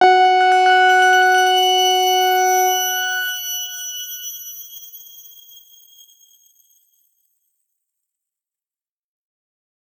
X_Grain-F#4-ff.wav